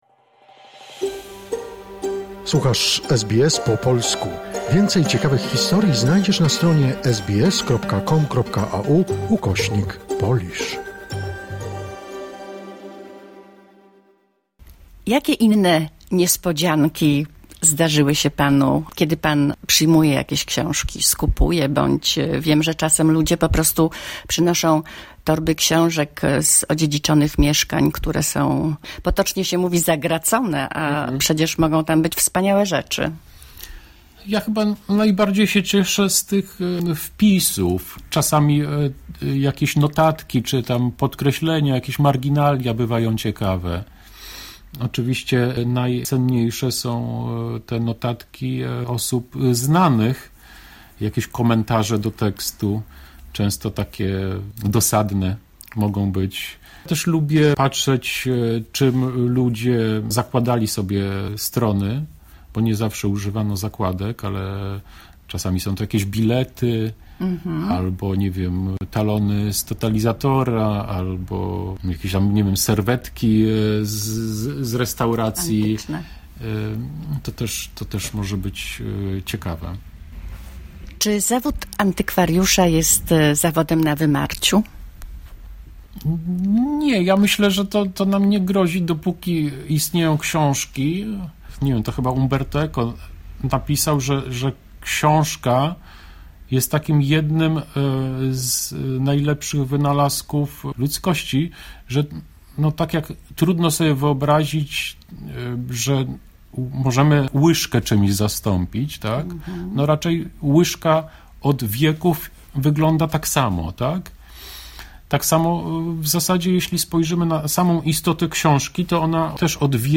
Przeprowadzona w Warszawie rozmowa